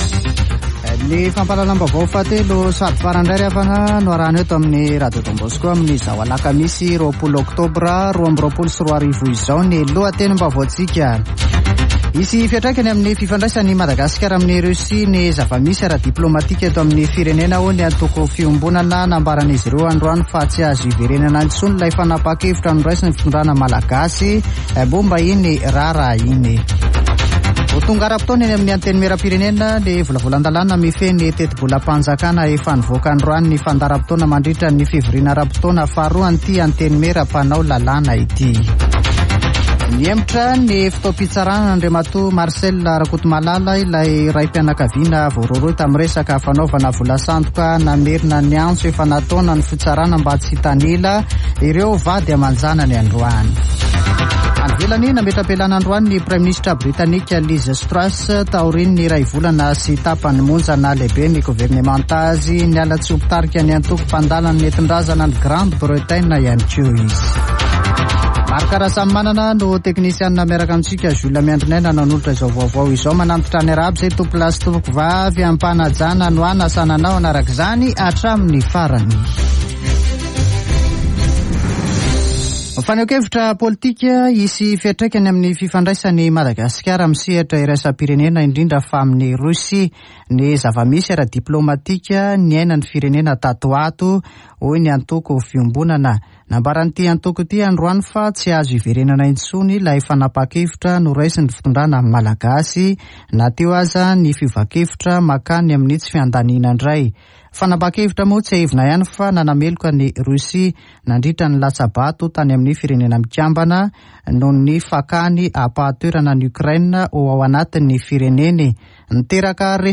[Vaovao hariva] Alakamisy 20 ôktôbra 2022